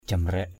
/ʥa-mrɛʔ/ (d.) máy móc.